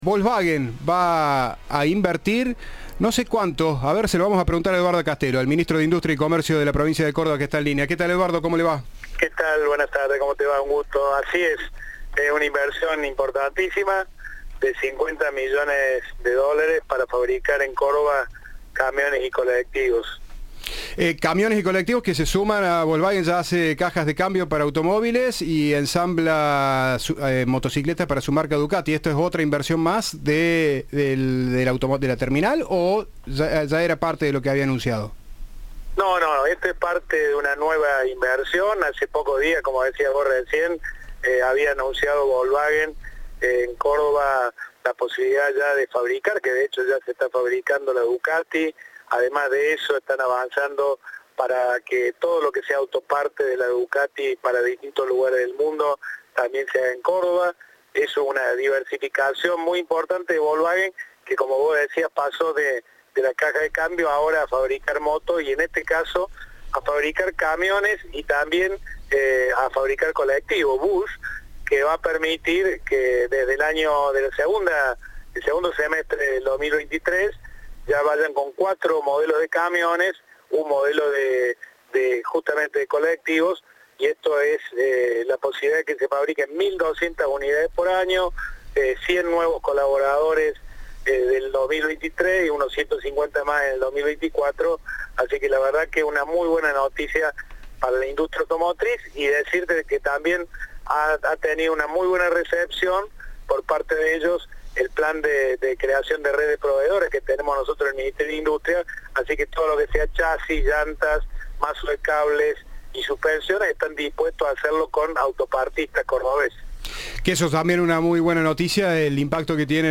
El ministro de Industria, Comercio y Minería de Córdoba explicó a Cadena 3 cómo será la adaptación que hará la empresa automotriz para fabricar camiones y colectivos en la provincia.
Entrevista